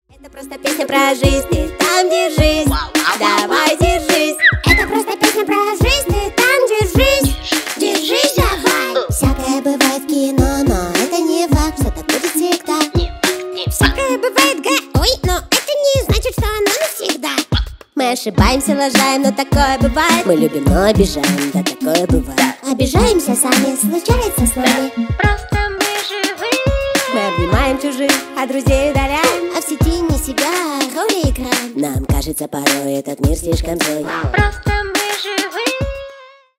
Поп Музыка
весёлые # милые